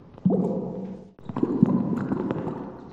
BottleOWater.mp3